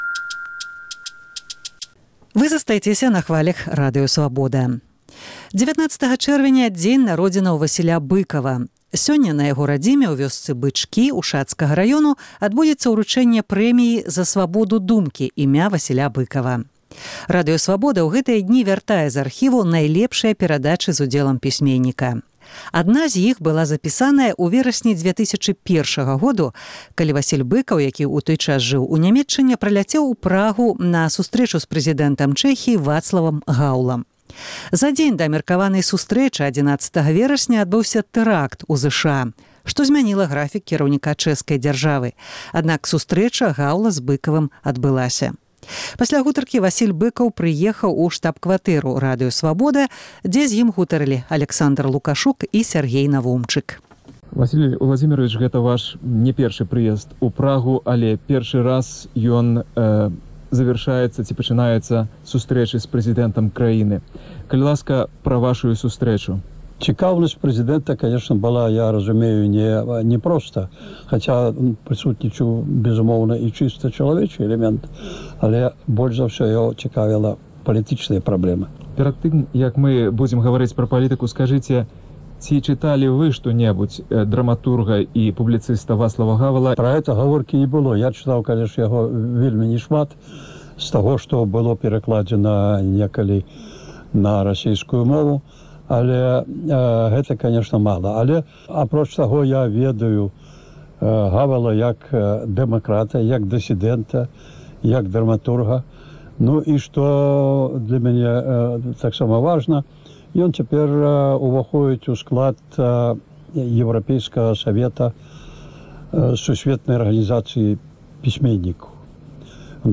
Радыё Свабода ў гэтыя дні вяртае з архіву найлепшыя перадачы з удзелам пісьменьніка. Адна зь іх была запісаная ў верасьні 2001 году, калі Васіль Быкаў, які ў той час жыў ў Нямеччыне, прыляцеў у Прагу на сустрэчу з прэзыдэнтам Чэхіі Вацлавам Гаўлам.